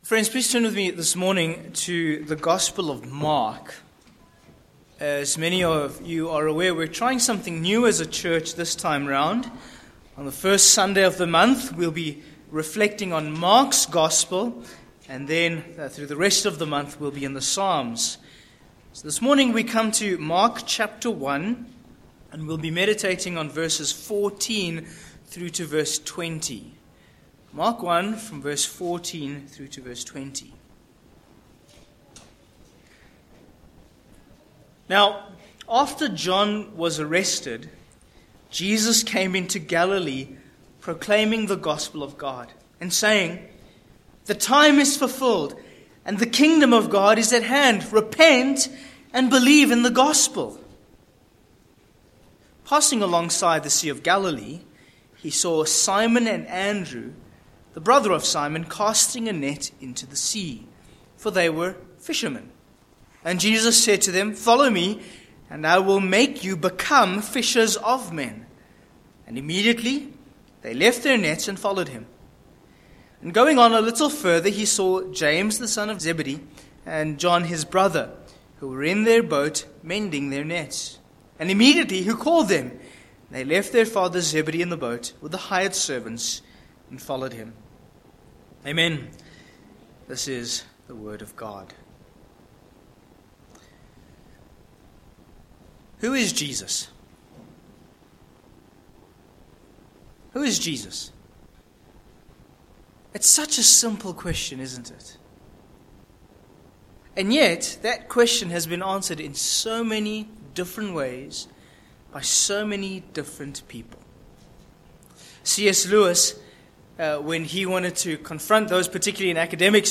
Sermon points: 1. The Setting v14a
Service Type: Morning